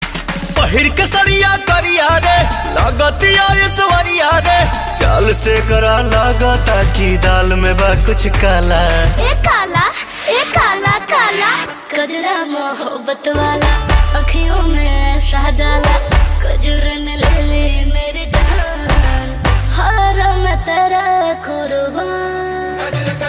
Bhojpuri Ringtones